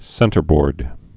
(sĕntər-bôrd)